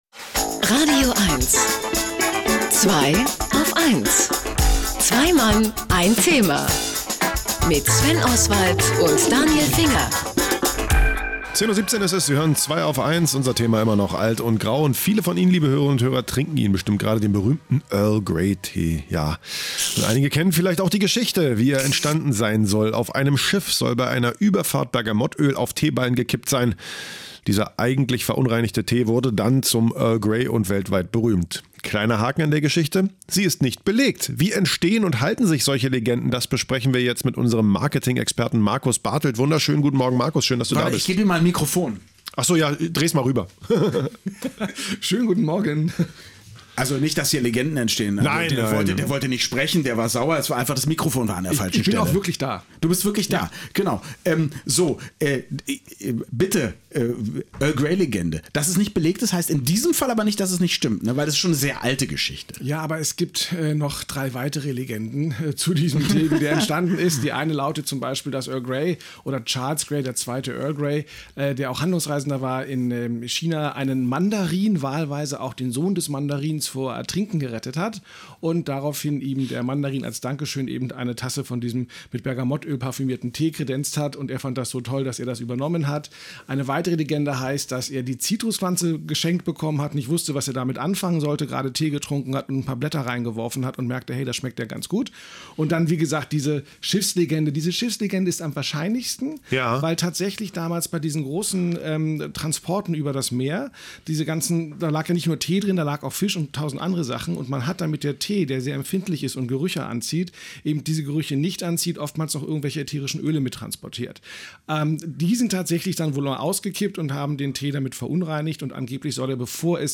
„Alt und grau“ war das Thema der „Zweiaufeins„-Sendung, zu der ich mich in das „radioeins„-Studio begab, um ein wenig über diese und andere Marketing-Legenden zu plaudern.